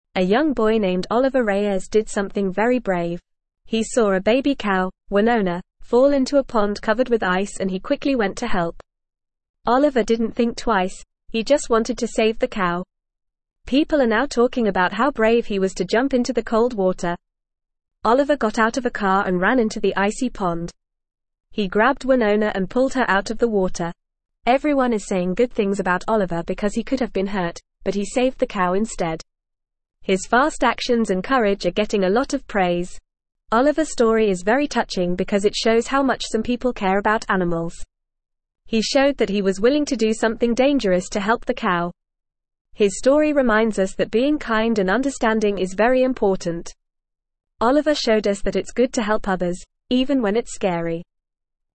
Fast
English-Newsroom-Lower-Intermediate-FAST-Reading-Brave-Boy-Saves-Cow-Stuck-in-Cold-Pond.mp3